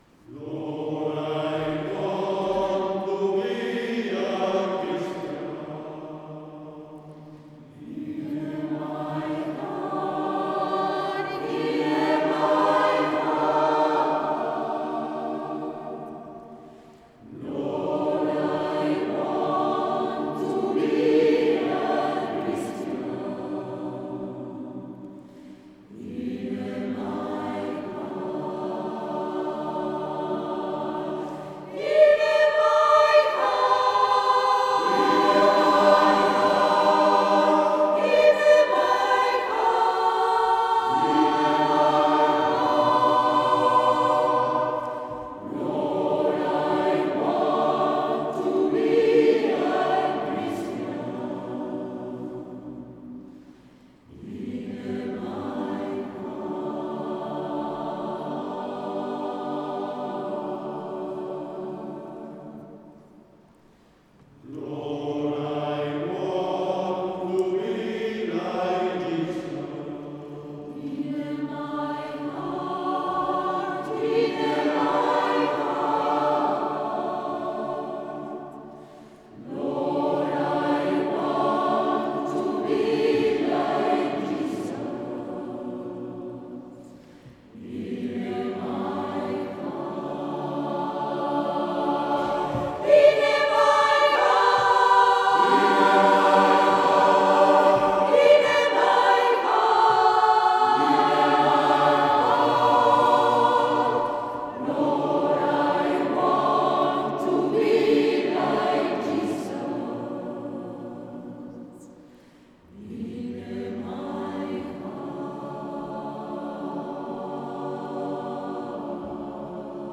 Es una  muestra de  canciones de diferentes estilos que hemos interpretado en los Conciertos (las grabaciones son en directo).